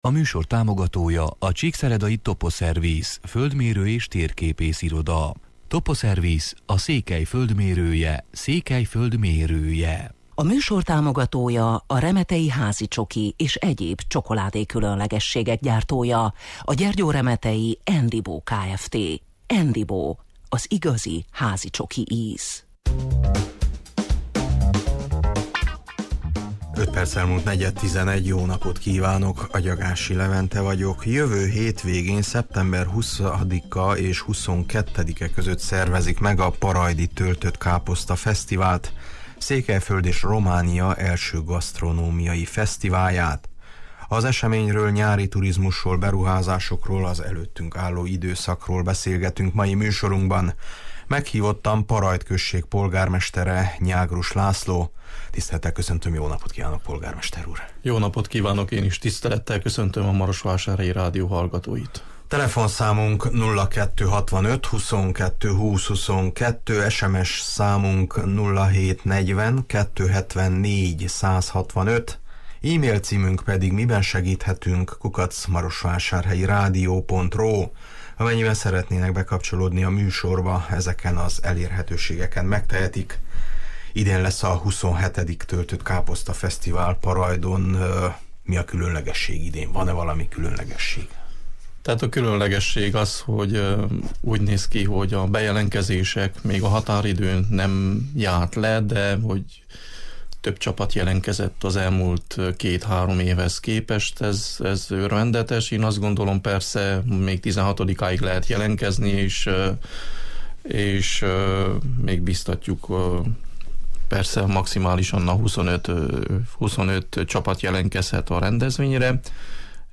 Szeptember 20-a és 22-e között szervezik meg a parajdi Töltöttkáposzta Fesztivált, Székelyföld és Románia első gasztronómiai fesztiválját. Az eseményről, a nyári turizmusról, beruházásokról, az előttünk álló időszakról beszélgetünk mai műsorunkban. Meghívottam Parajd község polgármestere, Nyágrus László: